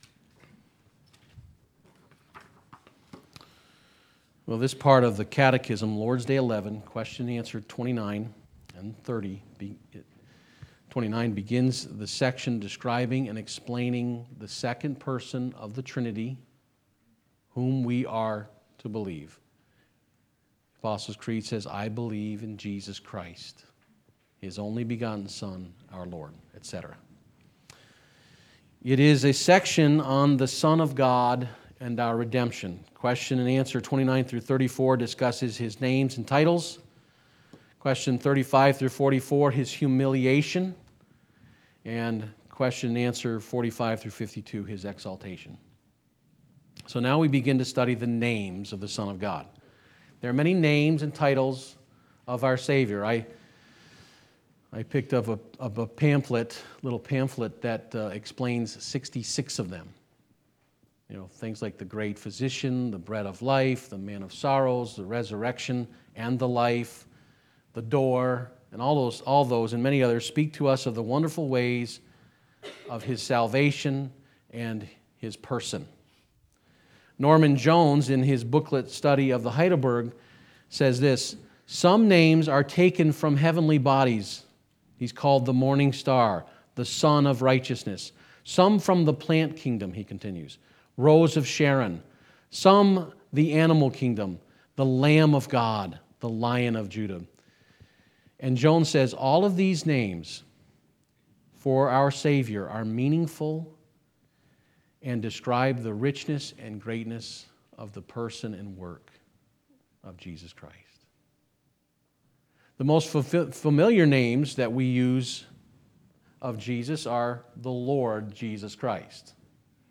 Sermons, Trinity Reformed Church, Cape Coral, FL Florida